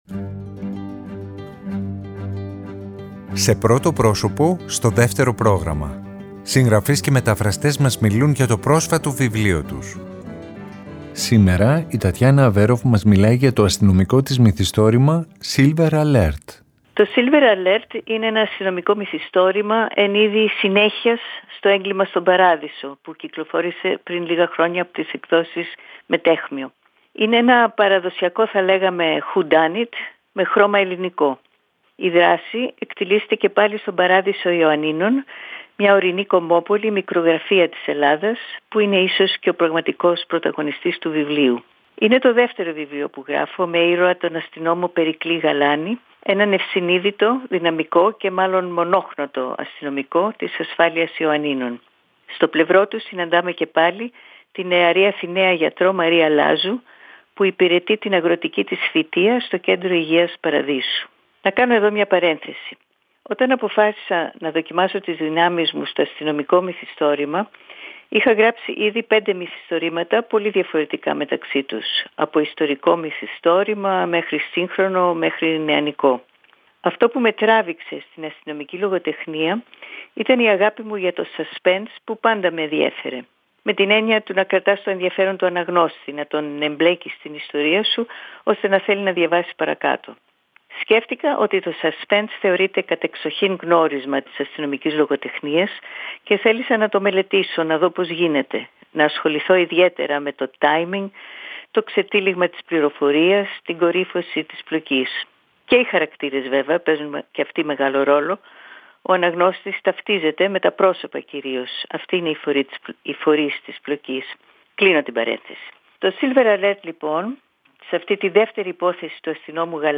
Σήμερα Δευτέρα, η Τατιάνα Αβέρωφ μας μιλάει για το βιβλίο της “Silver Alert”.